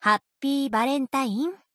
贡献 ） 协议：Copyright，作者： Cygames ，其他分类： 分类:富士奇石语音 您不可以覆盖此文件。